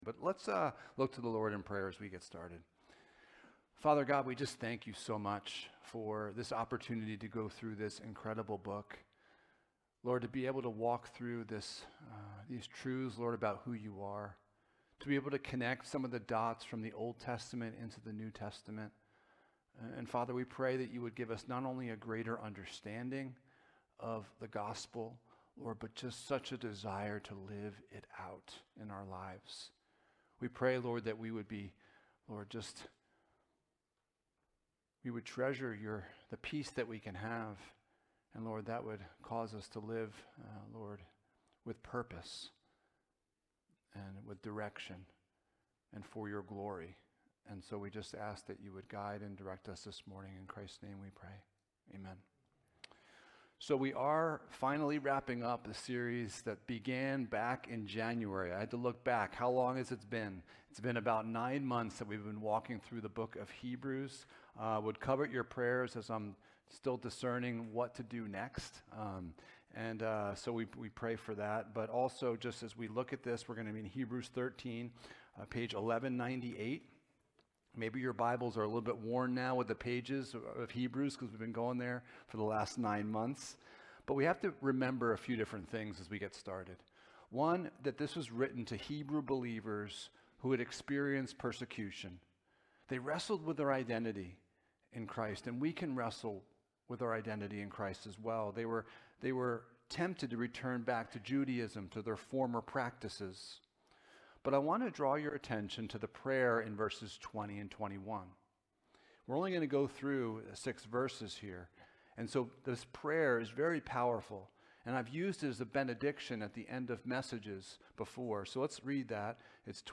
Jesus is Better Passage: Hebrews 13: 20-25 Service Type: Sunday Morning « What Happened to God’s Design for Church Leadership?